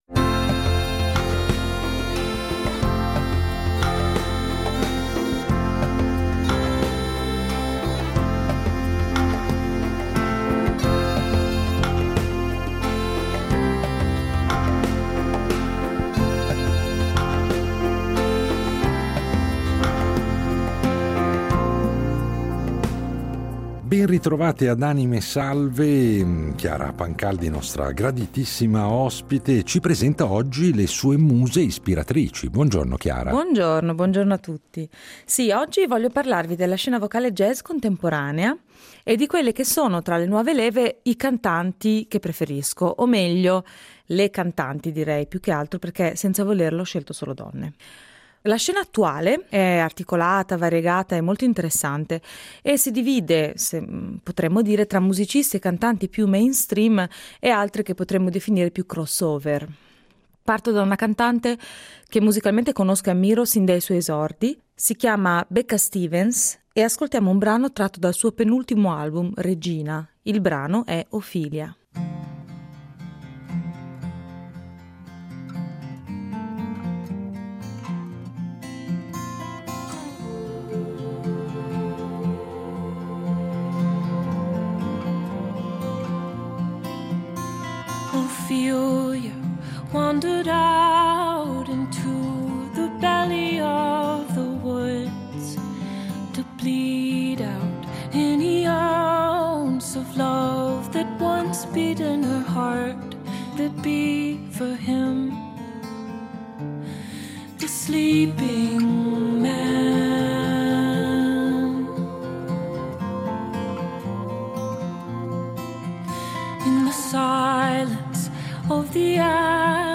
in versioni più intime